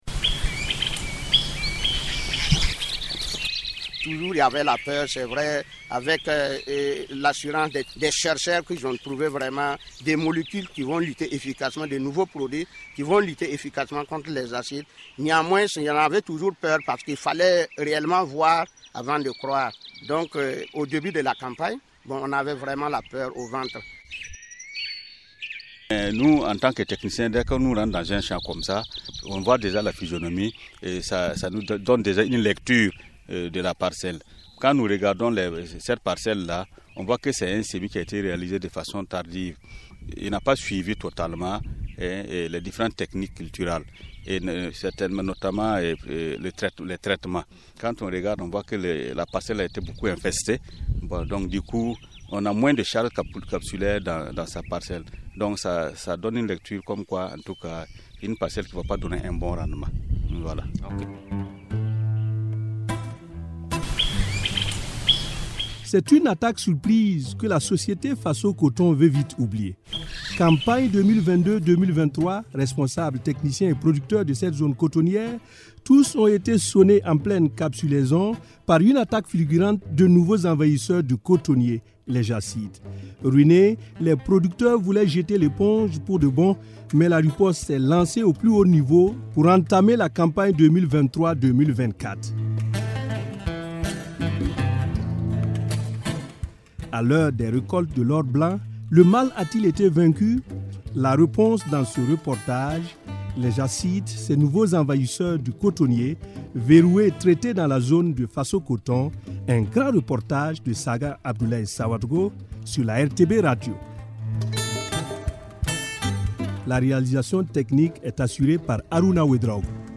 Nous sommes dans un champ cotonnier de faso-coton située à wayalguin au sud de zorgho. Un grand reportage